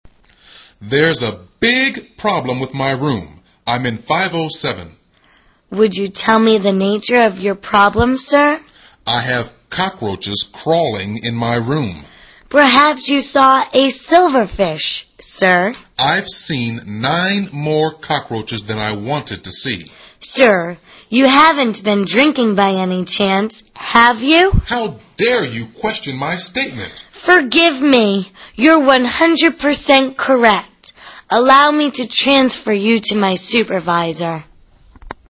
旅馆英语对话-Cockroach Problem(3) 听力文件下载—在线英语听力室